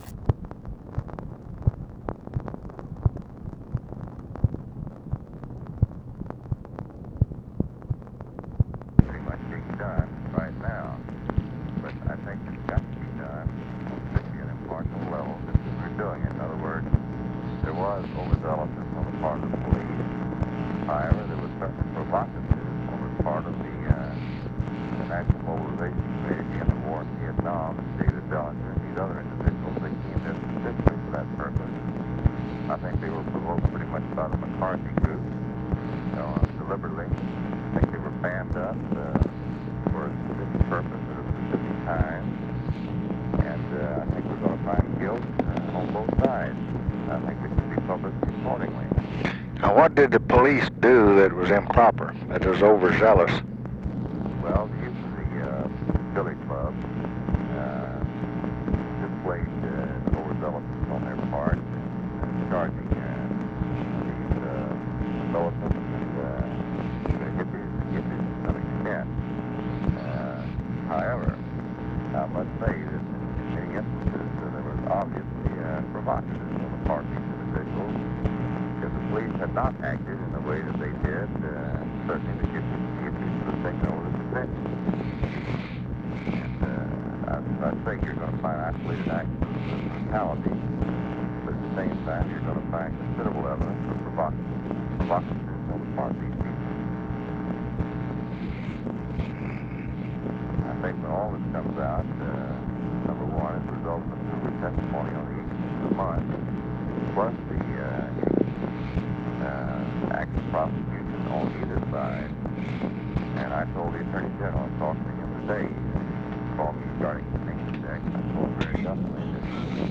Conversation with CARTHA DELOACH and OFFICE CONVERSATION, August 30, 1968
Secret White House Tapes